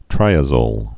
(trīə-zōl, trī-ăzōl)